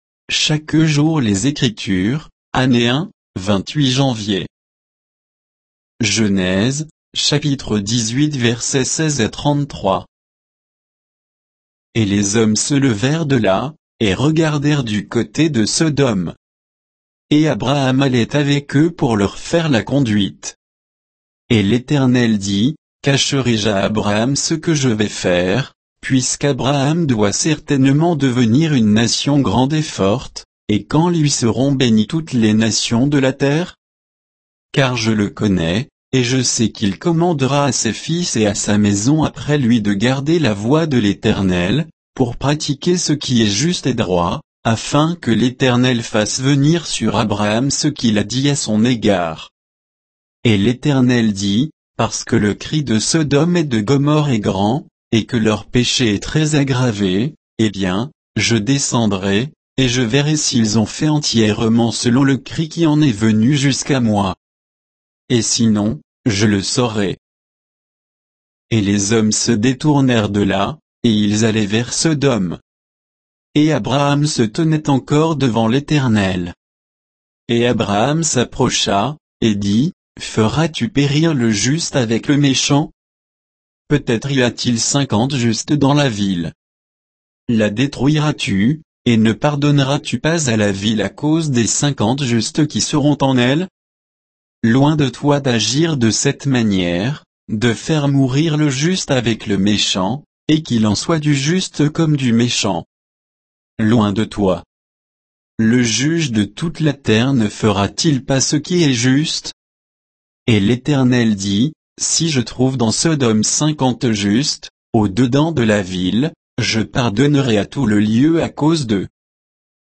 Méditation quoditienne de Chaque jour les Écritures sur Genèse 18, 16 à 33